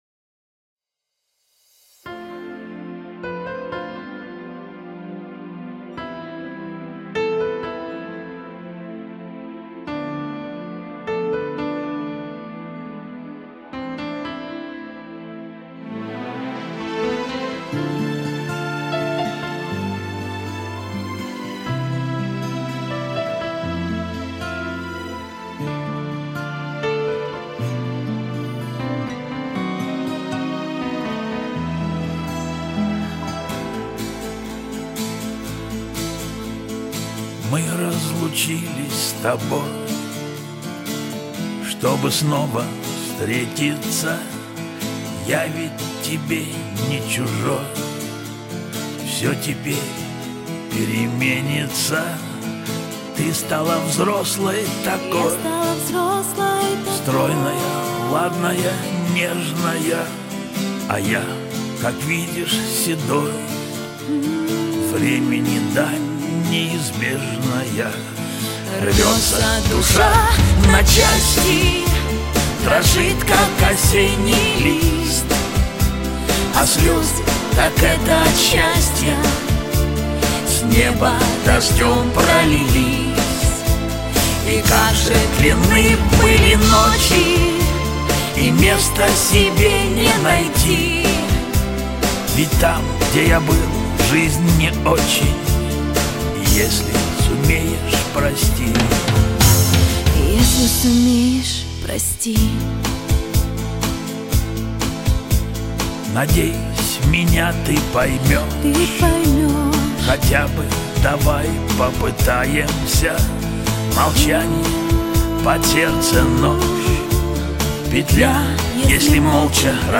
Жанр: Шансон